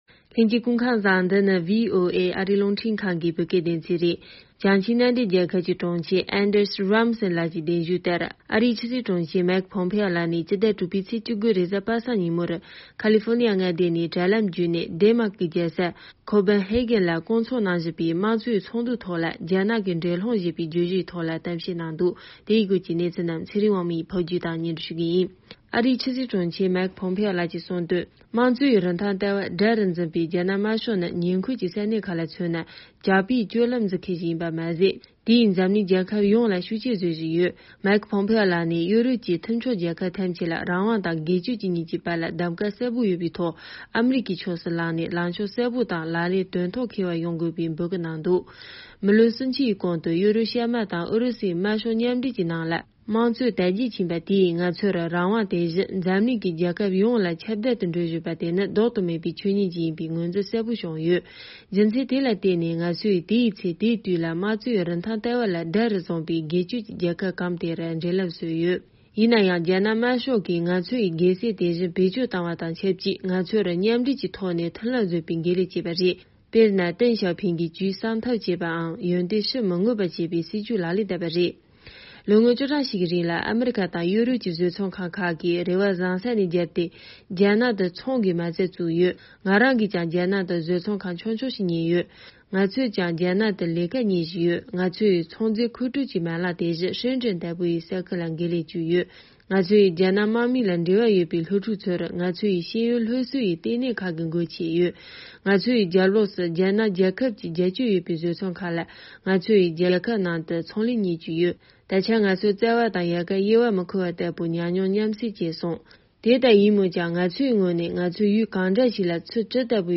ཁོང་གིས་རྒྱ་ནག་གི་འགྲན་སློང་ཞེས་པའི་བརྗོད་གཞིའི་ཐོག་ཏུ་གསུང་བཤད་གནང་འདུག